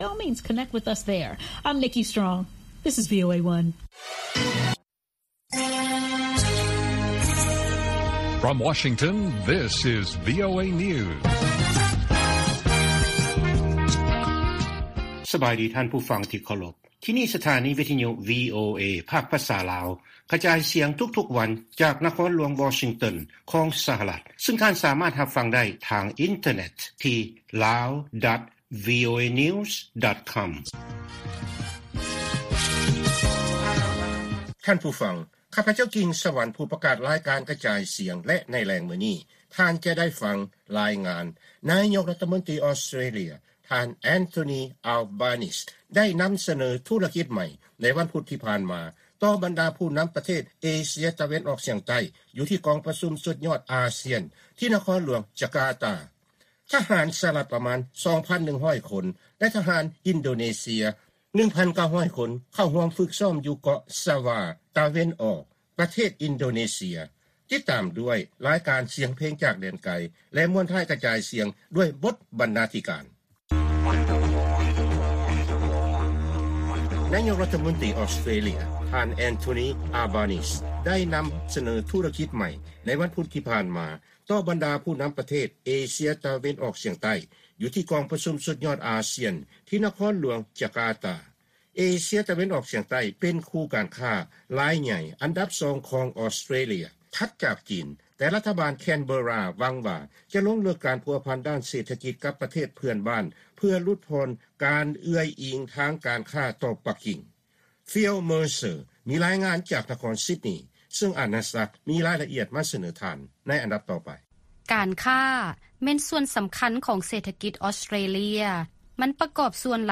ວີໂອເອພາກພາສາລາວ ກະຈາຍສຽງທຸກໆວັນ, ບົດລາຍງານໃນມື້ນີ້ມີ: 1. ອອສເຕຣເລຍ ພະຍາຍາມສົ່ງເສີມ ສາຍພົວພັນດ້ານການຄ້າ ກັບເອເຊຍຕາເວັນອອກສຽງໃຕ້, 2. ກອງທັບສະຫະລັດ ແລະກອງທັບອິນໂດເນເຊຍ ຈັດການຊ້ອມລົບຮ່ວມກັນ, ແລະ 3. .